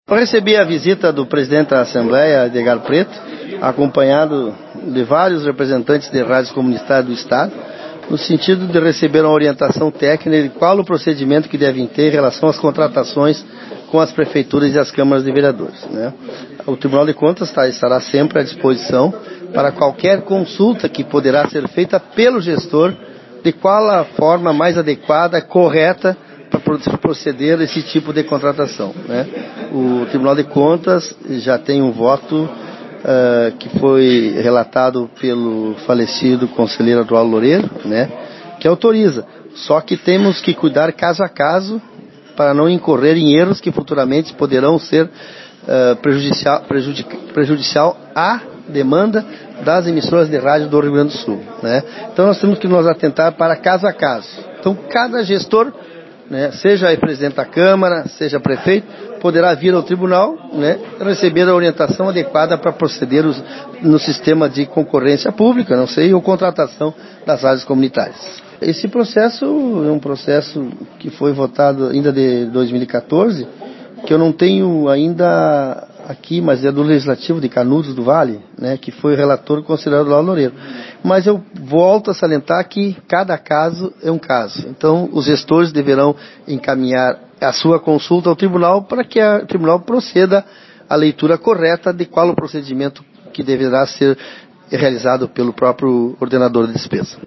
O presidente do TCE-RS, Marco Peixoto, falou sobre o papel do Tribunal de Contas em relação aos procedimentos de contratações com as Prefeituras e Câmaras de Vereadores: